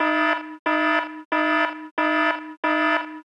MsgSosSiren.wav